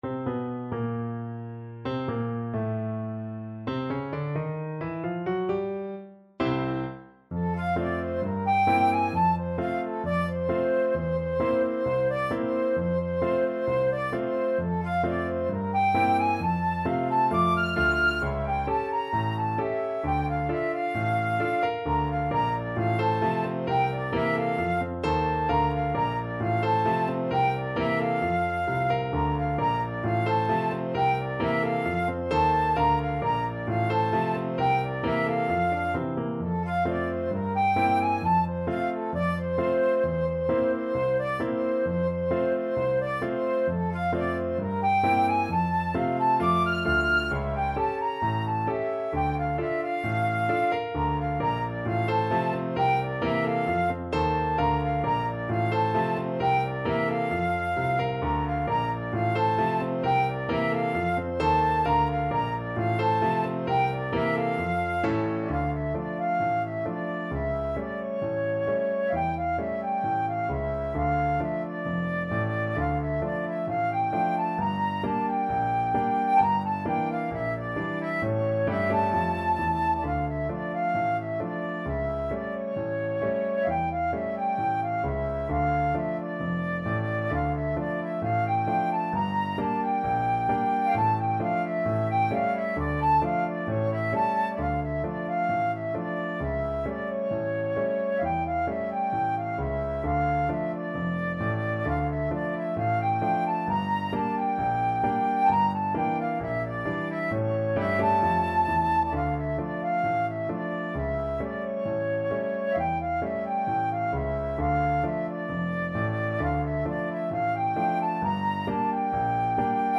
Flute
F major (Sounding Pitch) (View more F major Music for Flute )
4/4 (View more 4/4 Music)
= 132 Allegretto ma non troppo
A5-F7
Jazz (View more Jazz Flute Music)